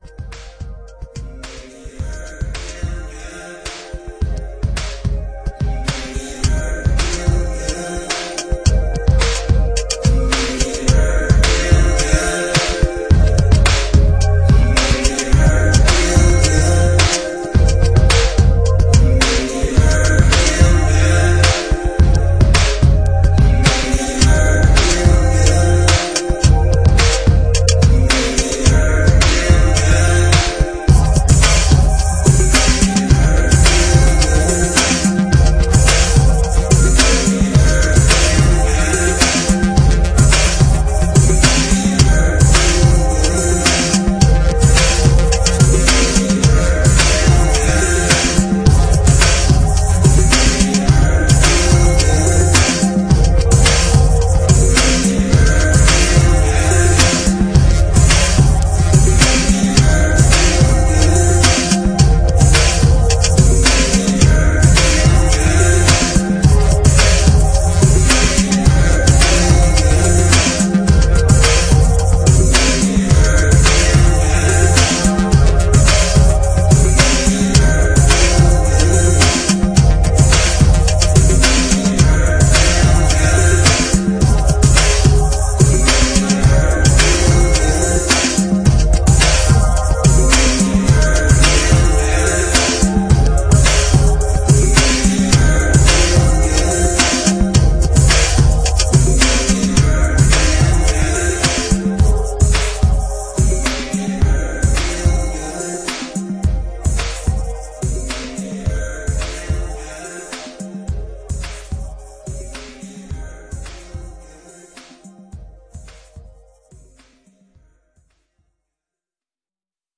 futuristic sleaze